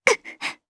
Isaiah-Vox_Damage_jp.wav